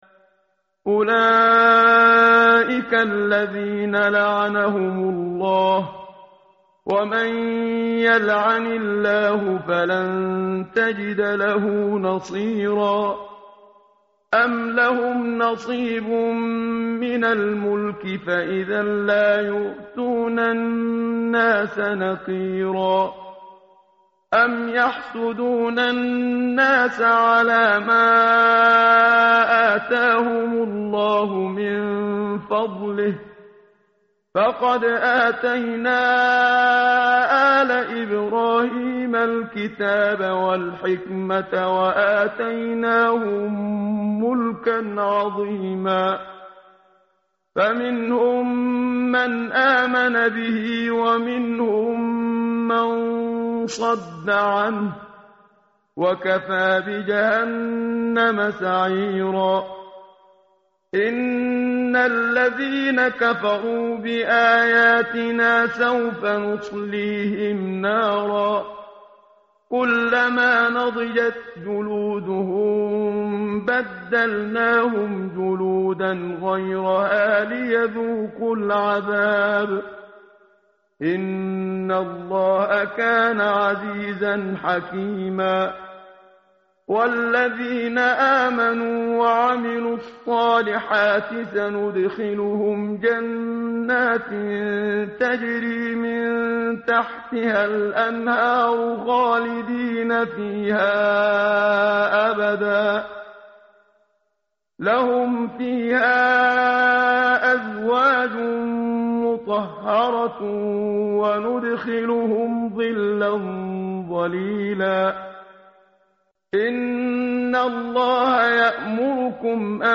tartil_menshavi_page_087.mp3